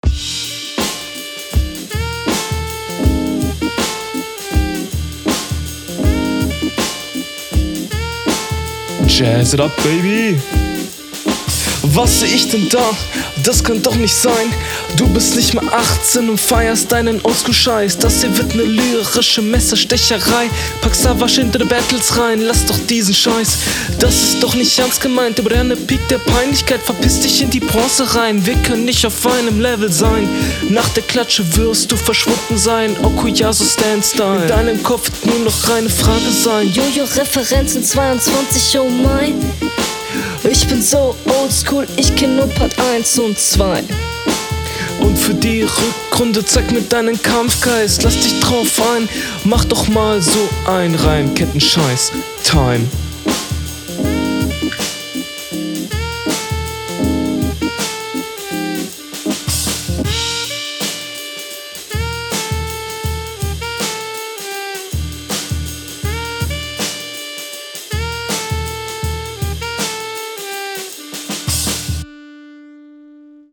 HR1: Oha nicer Beat, Stimme klingt aber leider nicht so gut drauf, Mix sehr unschön.